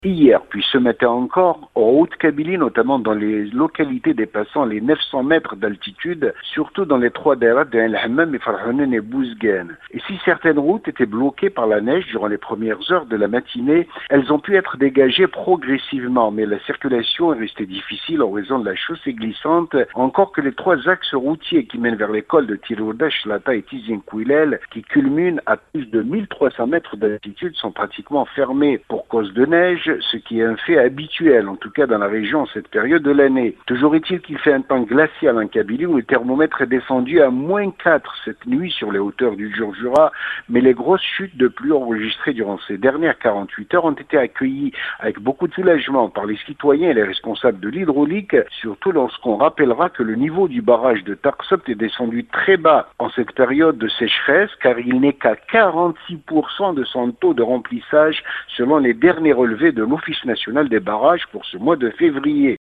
Correspondance, depuis Tizi-Ouzou,